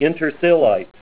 Help on Name Pronunciation: Name Pronunciation: Intersilite + Pronunciation